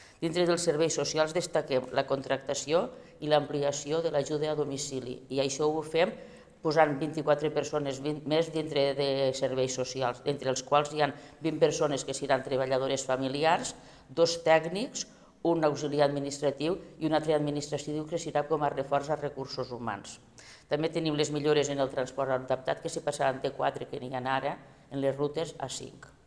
En l’àmbit dels serveis socials, la vicepresidenta, Mercè Pedret ha subratllat la importància d’ampliar el personal d’atenció a domicili, amb 24 noves contractacions, així com l’augment de les rutes de transport adaptat, passant de quatre a cinc.